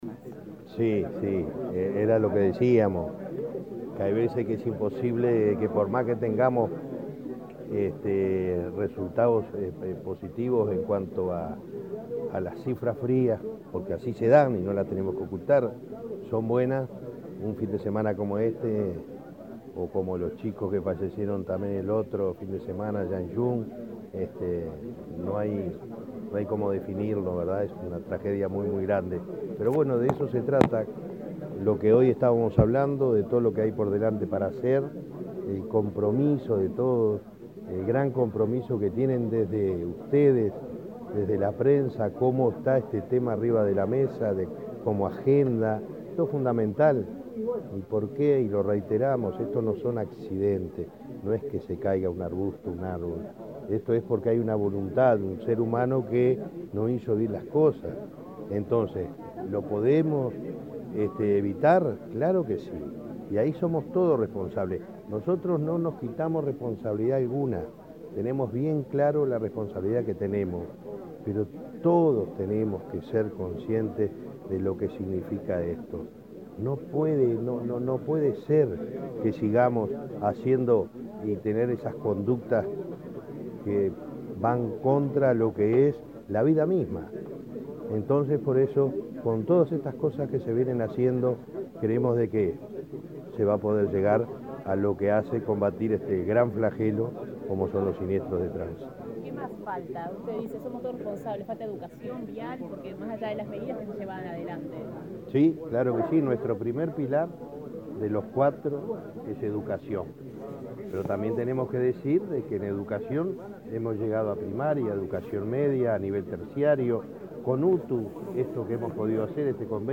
Declaraciones del presidente de Unasev, Alejandro Draper
Este lunes 9, en Montevideo, el presidente de la Unidad Nacional de Seguridad Vial (Unasev), Alejandro Draper, dialogó con la prensa, luego de